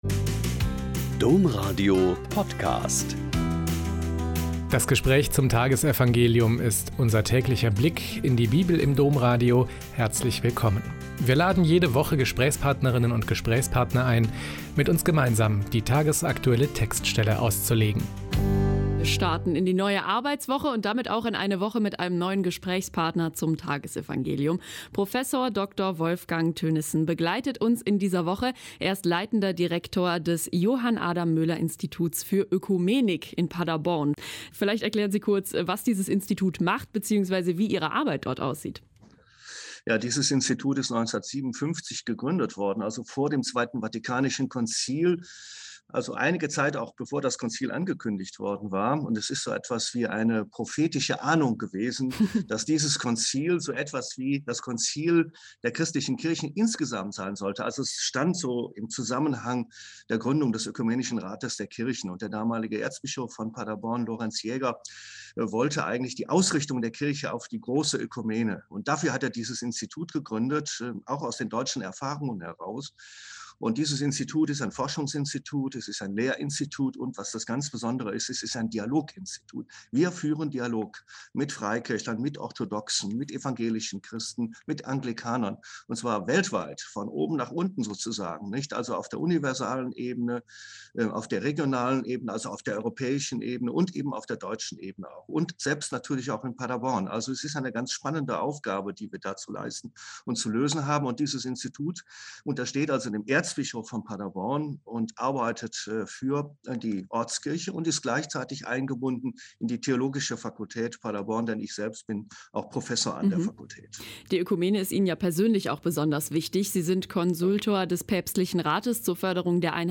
Joh 12,1-11 - Gespräch